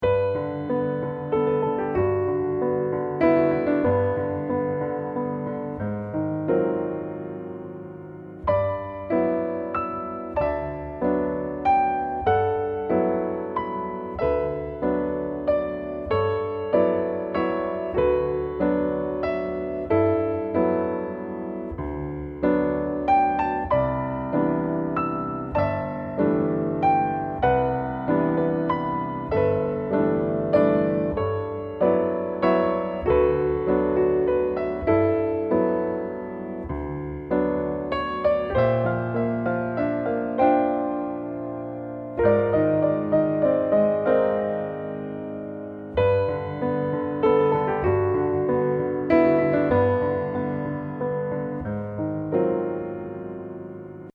Level : Intermediate | Key : B-flat | Individual PDF : $3.99